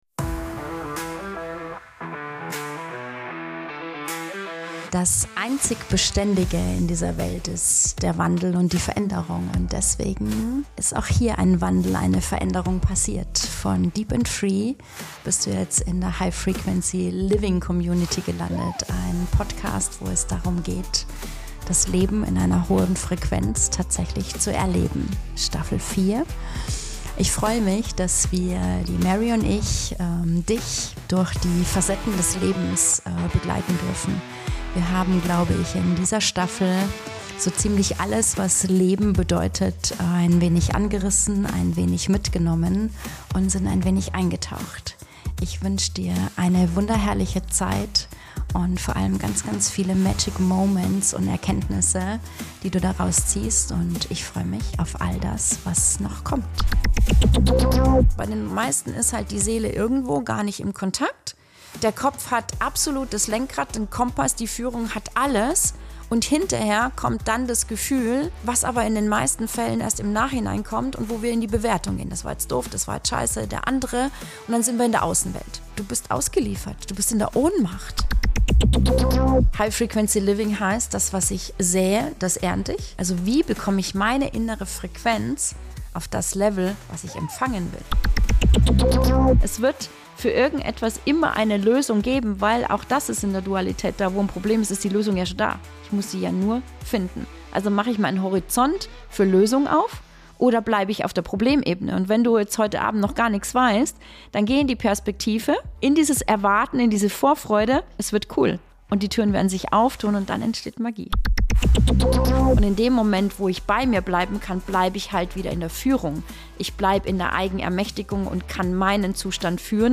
ungeskriptet, roh, lebendig.